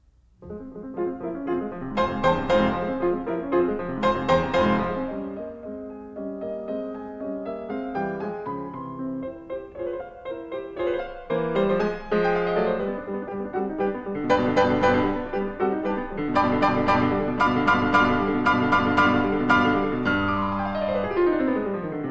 Fourth movement – Pressissimo, F minor, sonata form
The feel of this movement – as it begins – is very dramatic, harsh.